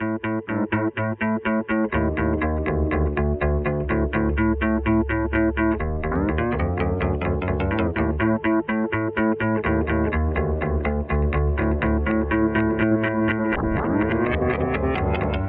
描述：用REAKTOR制作的古怪循环和垫子
Tag: 92 bpm Weird Loops Fx Loops 1.75 MB wav Key : Unknown